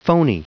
Prononciation du mot phoney en anglais (fichier audio)
phoney.wav